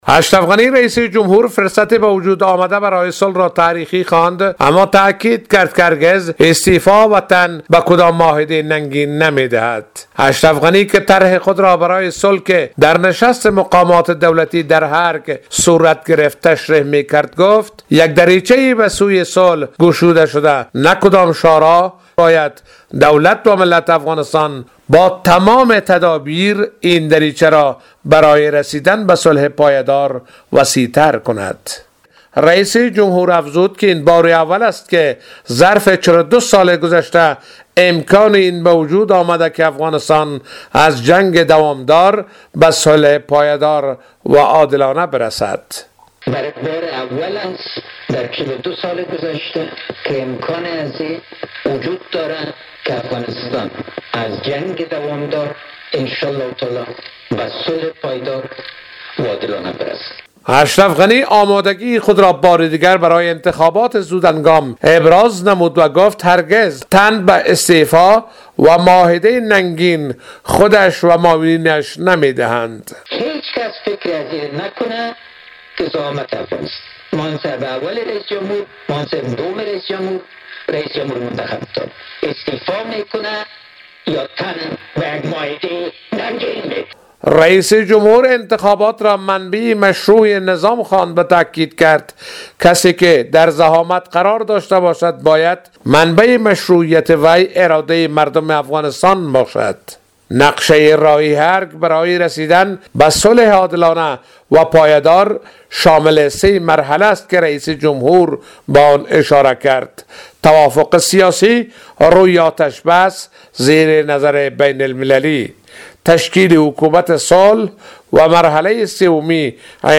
به گزارش خبرنگار رادیودری، اشرف غنی که طرح صلح خود را برای نشست استانبول برای مقامات دولتی در ارگ از طریق ویدیو تشریح می کرد، گفت: یک دریچه برای صلح گشوده شده نه شاهراه و دروازه.